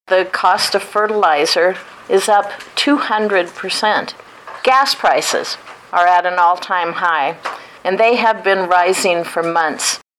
Nebraska Senator Deb Fisher says farmers are making planting decisions now but face big uncertainty over costs and markets.